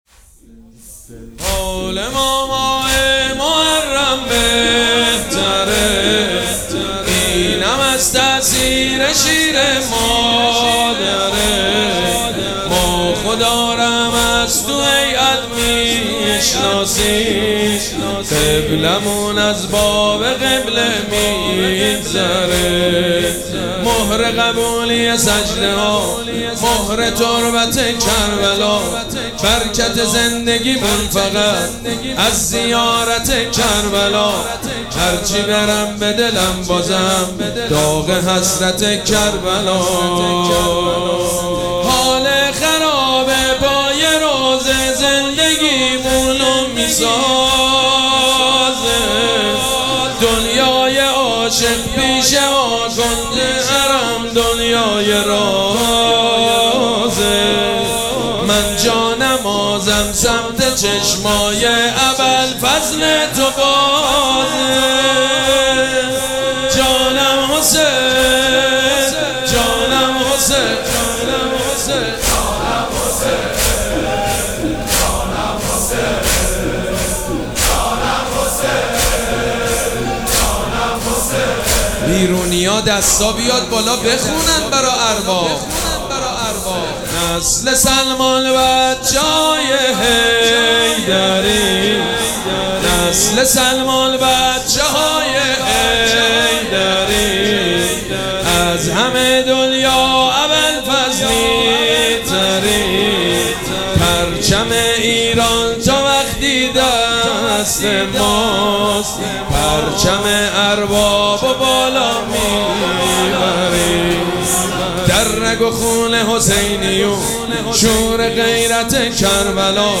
مراسم عزاداری شب سوم محرم الحرام ۱۴۴۷
مداح
حاج سید مجید بنی فاطمه